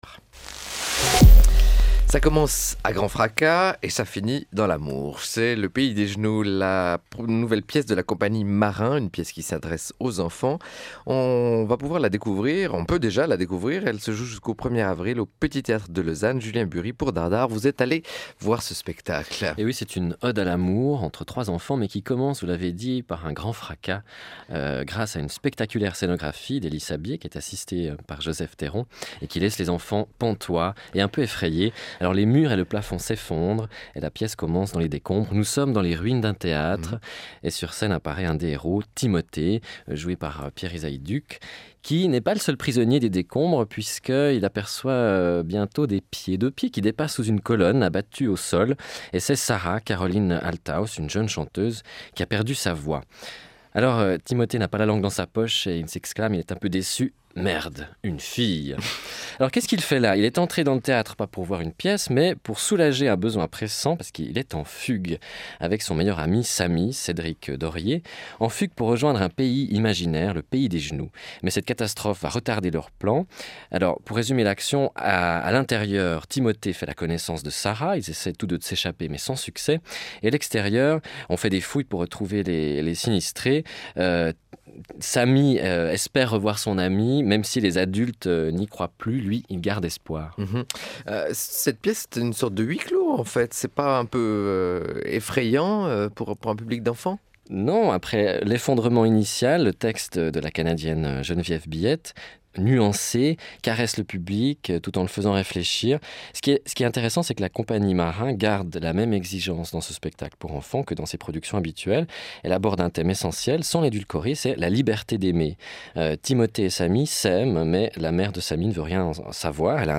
interview rsr pdg.mp3